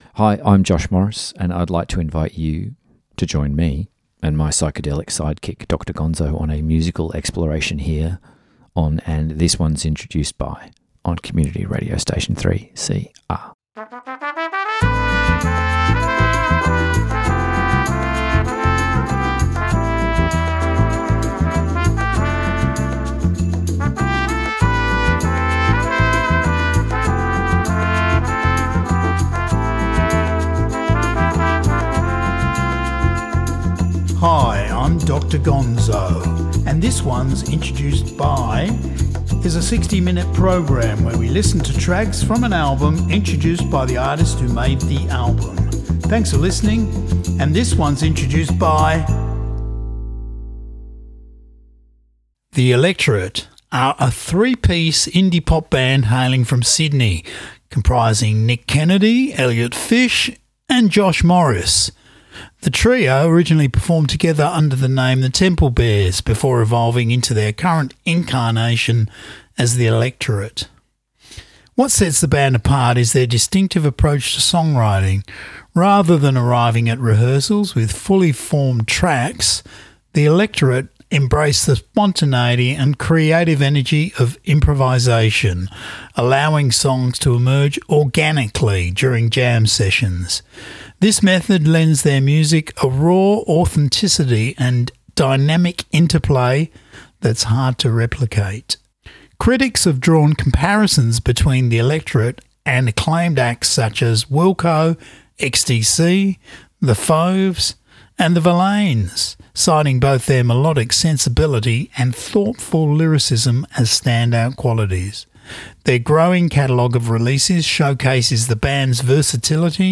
The result is a polished yet intimate record that captures the band’s evolution and artistic vision.